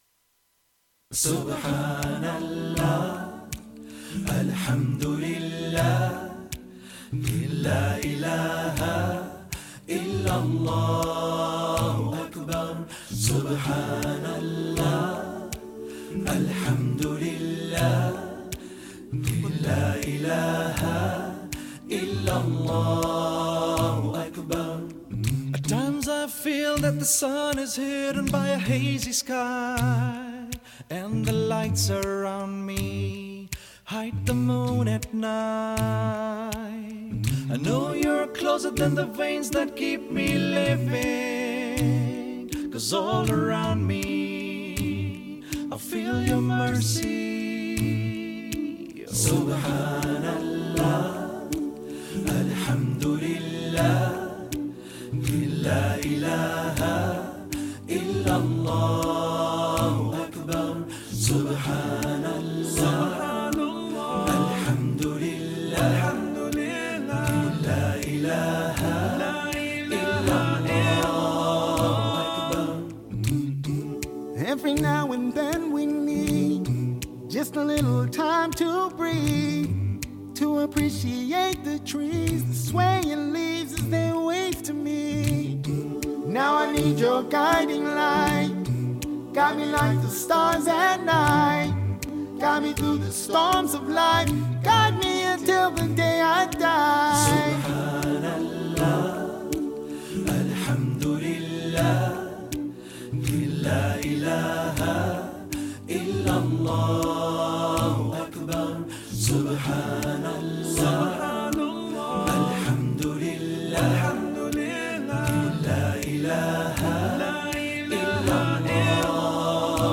Nashid